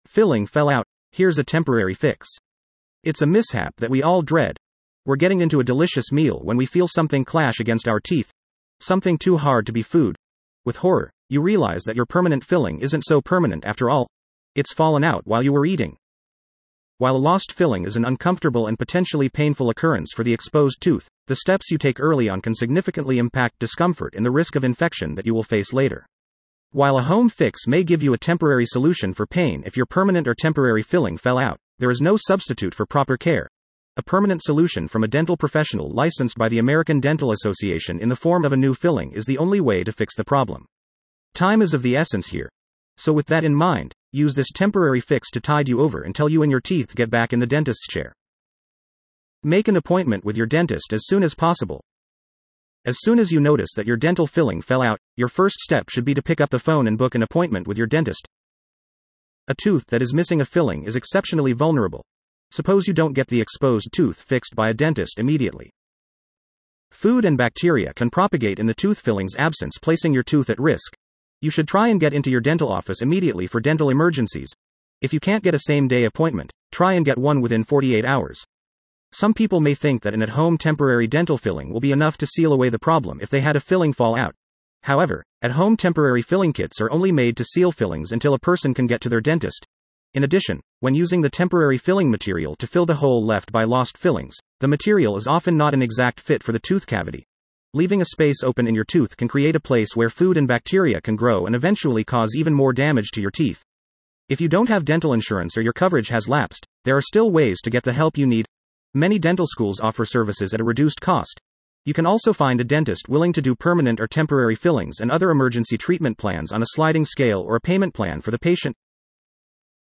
Patient-Empowered-Dentistry-Filling-Fell-Out-Heres-a-Temporary-Fix-Voiceover.mp3